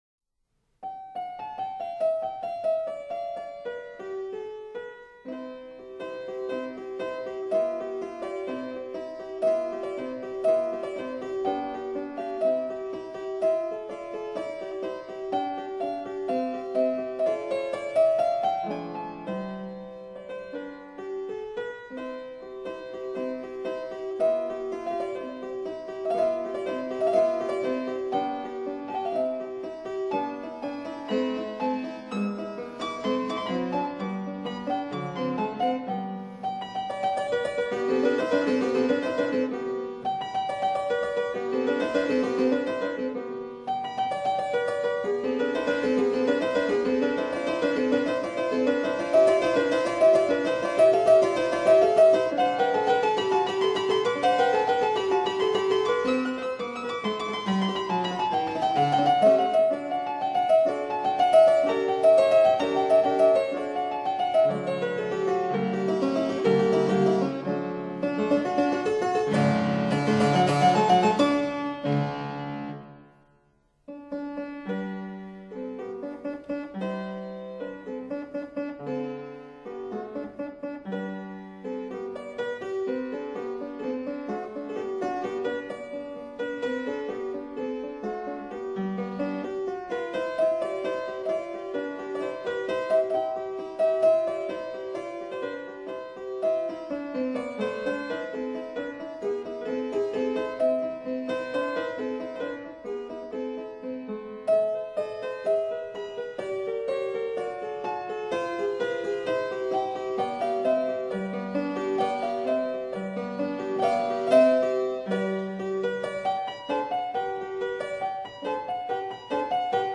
for piano in C major